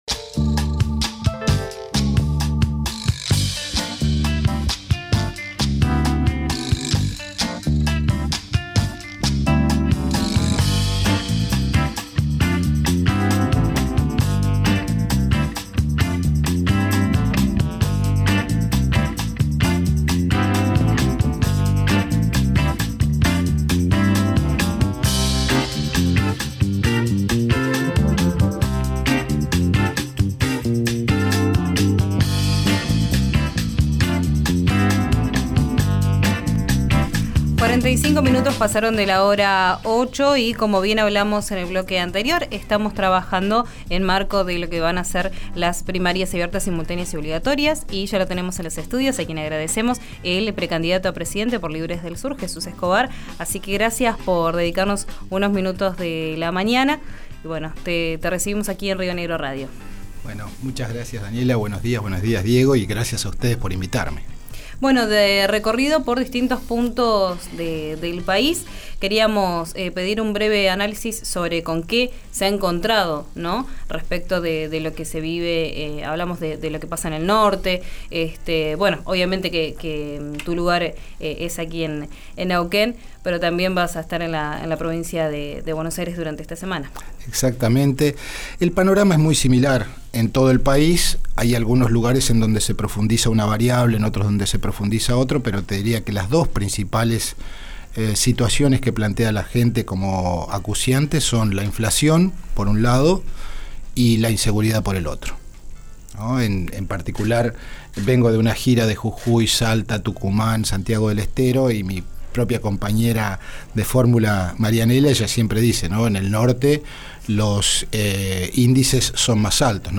encabezó un acto proselitista este sábado en el gimnasio de Gregorio Álvarez, en el oeste de la ciudad de Neuquén.